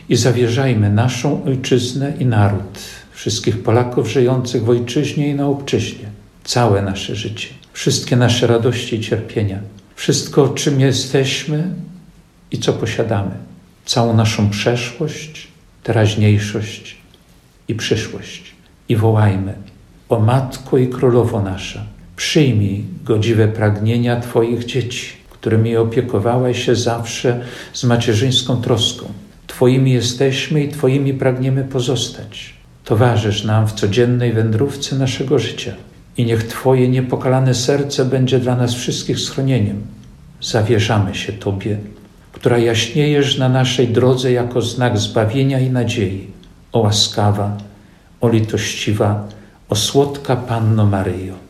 – Łączmy się tego dnia duchowo – dodaje biskup Jerzy Mazur.